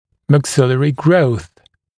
[mæk’sɪlərɪ grəuθ][мэк’силэри гроус]рост верхней челюсти